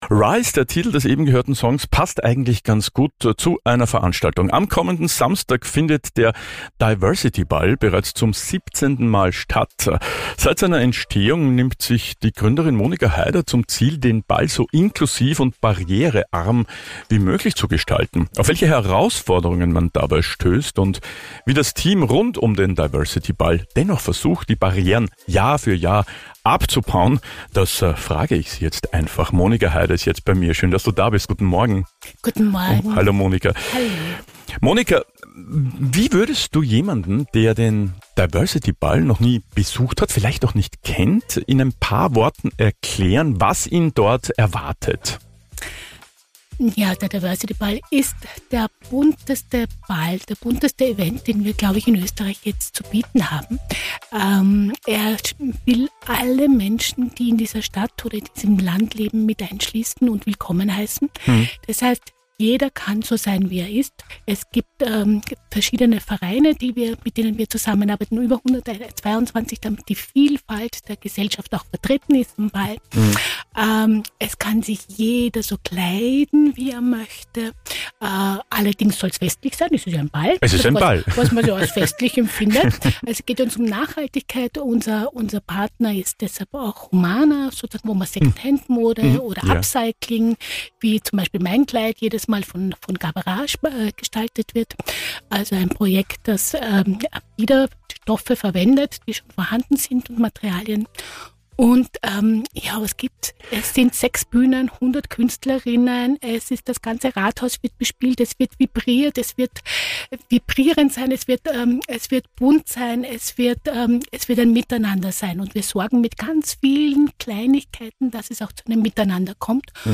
Superfly Interviews